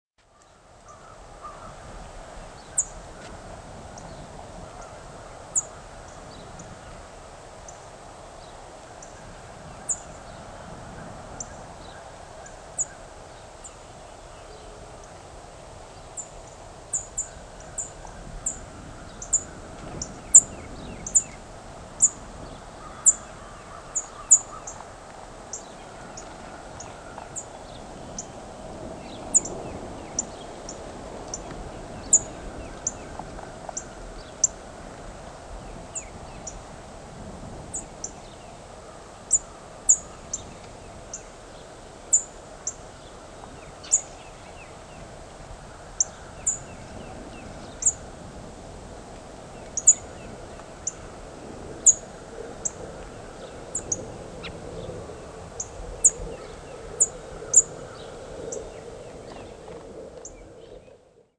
American Tree Sparrow diurnal flight calls
Diurnal calling sequences:
Several perched birds.